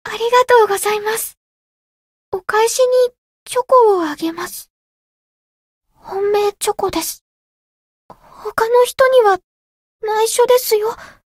灵魂潮汐-梦咲音月-情人节（送礼语音）.ogg